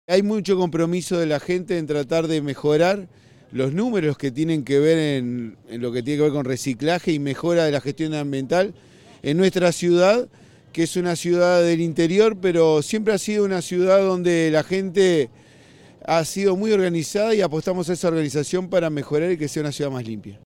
En tanto, el Alcalde Fernández aseguró que “hay mucho compromiso de la gente por tratar de mejorar la gestión ambiental de la ciudad, con personas muy organizadas, por eso apostamos a que sea una ciudad más limpia”.